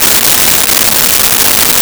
Vacuum Cleaner Loop 02
Vacuum Cleaner Loop 02.wav